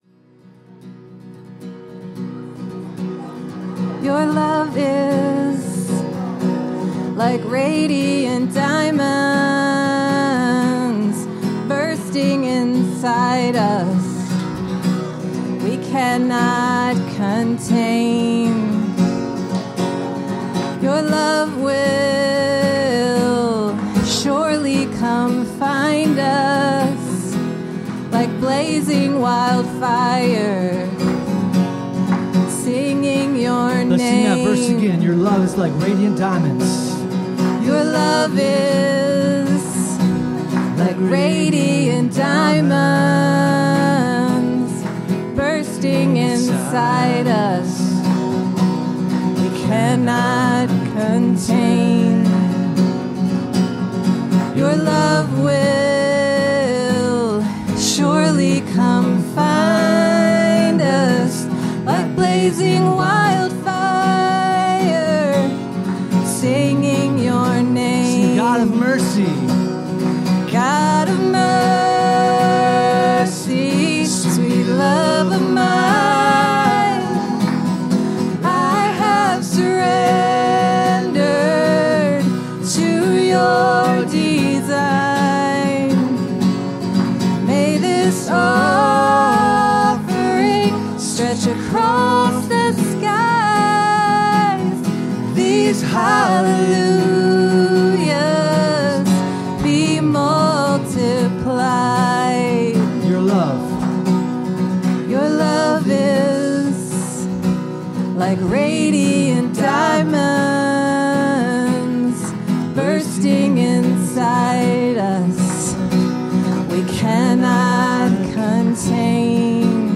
Worship 2025-03-30